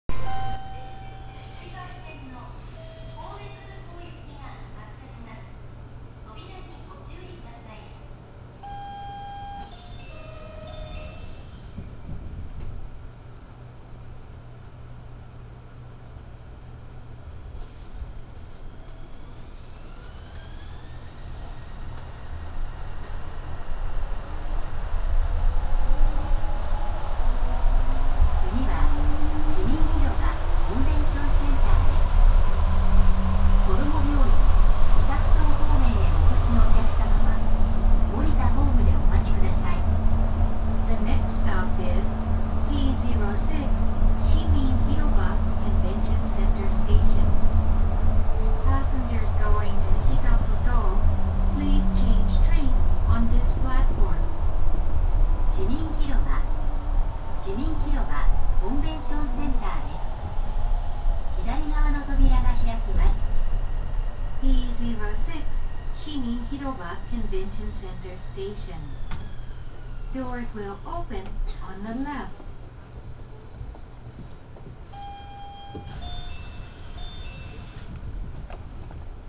・2020型走行音
【ポートライナー】みなとじま→市民広場（1分19秒：432KB）
三菱IGBTなのは2000型同様。一応2000型の物から更新された事で省エネ化が図られているらしいのですが、走行音に変化は無い様に感じました。ドアブザーとドアチャイムが両方ついているのが面白い所でしょうか。